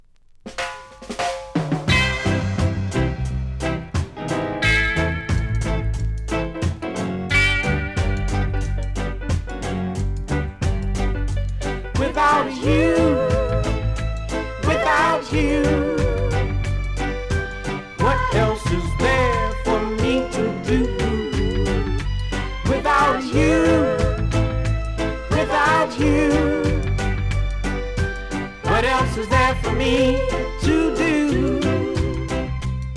ジャマイカで人気だったアメリカのソウル・シンガー♪